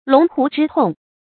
注音：ㄌㄨㄙˊ ㄏㄨˊ ㄓㄧ ㄊㄨㄙˋ
龍胡之痛的讀法